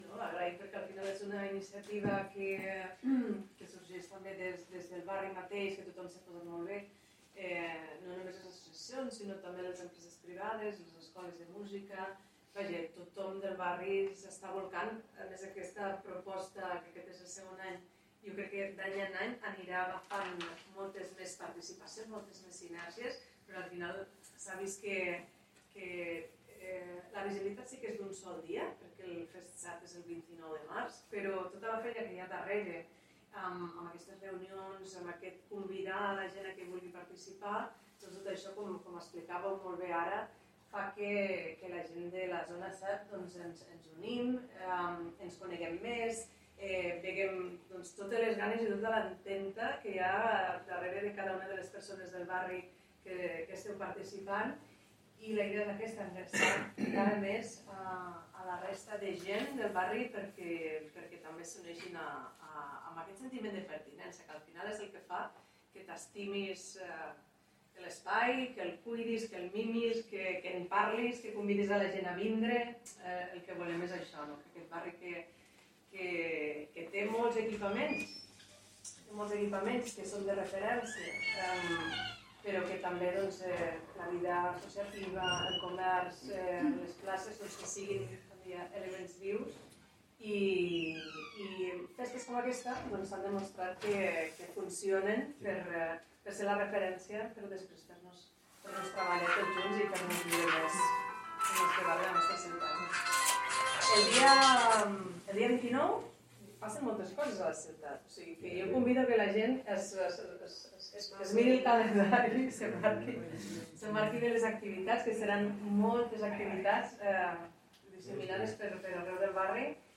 Talls de veu
Intervenció del Comissionat de l'Alcaldia pel pacte pel civisme i la ciutat dels valors de l'Ajuntament de Lleida, Carles Alsinet, en la roda de premsa de presentació de la proposta inicial del Pacte per la Convivència i el Civisme a la ciutat de Lleida